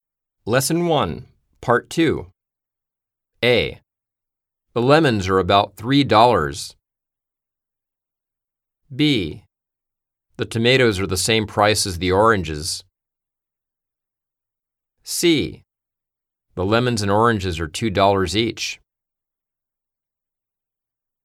*実際の『いいずなボイス』アプリ・音声CDでは，書き取りをするために音の流れない無音の時間がありますが，このサンプル音声では省略しています。